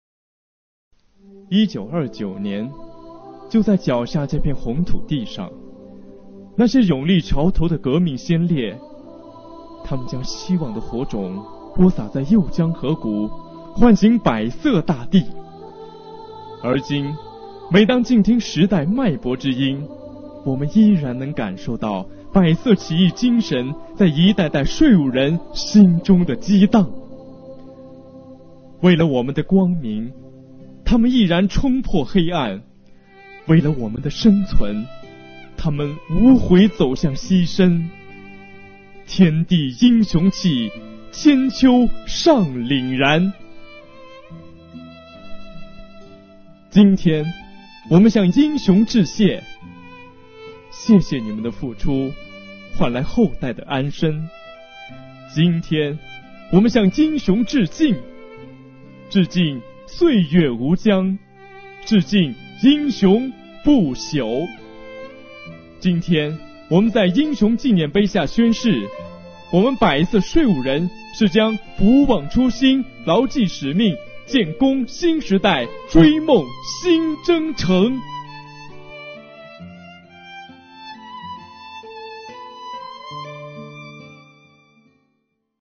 税务干部在纪念碑下宣誓必将不忘初心牢记使命， 建功新时代， 追梦新征程。